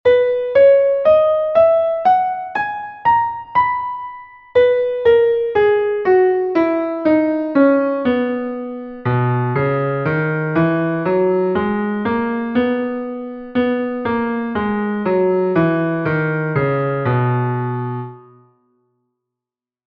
H-Dur
Die Tonart H-Dur, ihre Tonleiter aufsteigend und absteigend, notiert im Violinschlüssel und Bassschlüssel.
H Cis Dis E Fis Gis Ais